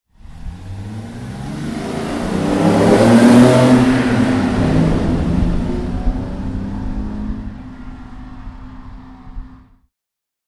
Mercedes-Benz 300 SL (1960) - losfahren
Mercedes_300_SL_Start.mp3